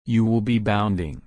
/ˈbaʊnd/